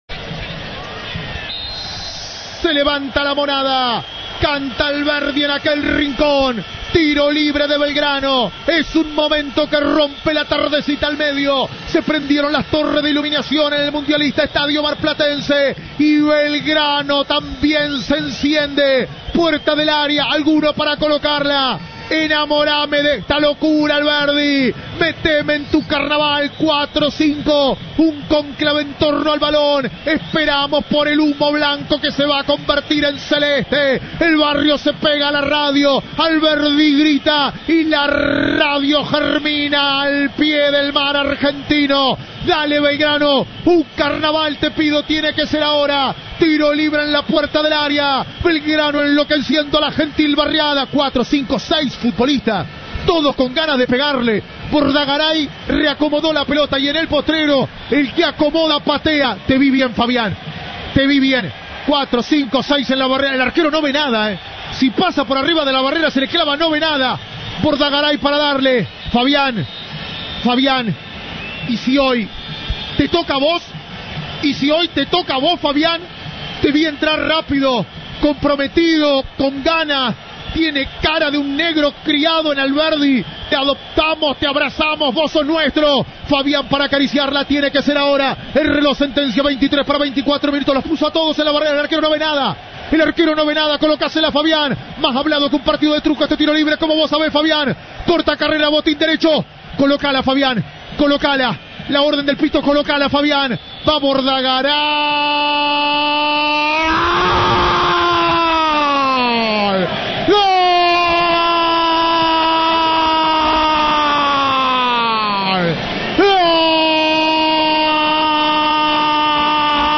Volvé a emocionarte con el grito "pirata" en Mar del Plata